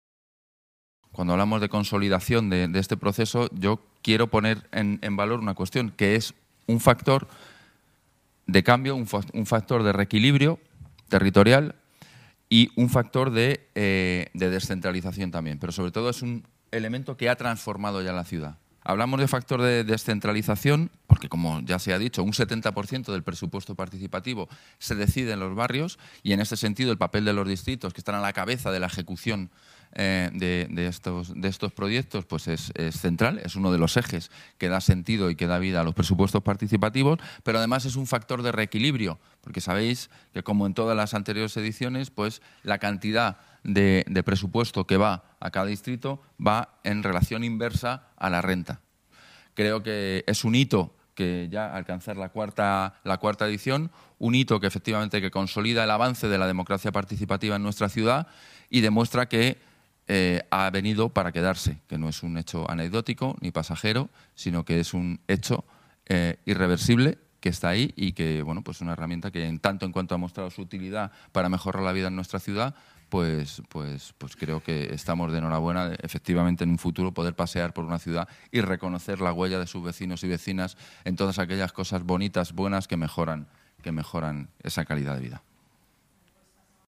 El delegado de Participación Ciudadana, Transparencia y Gobierno Abierto, Pablo Soto, junto al delegado de Coordinación Territorial y Cooperación Público-Social, Nacho Murgui, ha presentado el proceso participativo en el Palacio de Cibeles. Como en años anteriores, de esos 100 millones reservados para los proyectos de la ciudadanía, 30 están destinados a ideas que afecten a toda la ciudad.